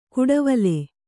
♪ kuḍavale